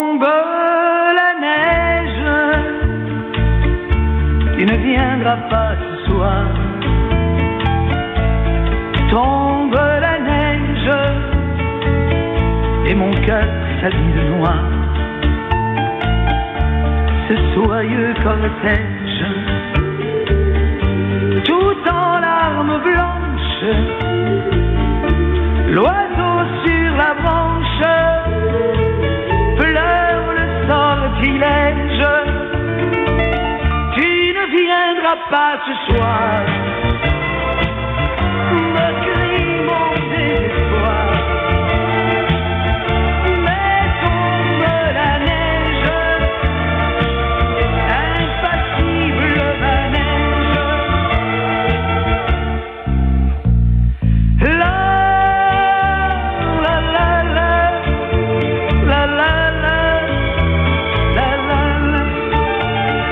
受信音を録音してみました。
いずれもANT端子信号レベル＝約S9+20ｄBで、AGC-VR＝若干下げている。
①　送信モード＝ISB、帯域＝3.5KHｚ　　　受信機モード＝ISB、LPF=3.6KHｚ
ISB受信録音＝1分間
LSB＝モノラル音、ISB=ステレオ音となります。